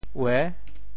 卫（衛） 部首拼音 部首 卩 总笔划 3 部外笔划 1 普通话 wèi 潮州发音 潮州 uê6 文 中文解释 衛 <動> (會意。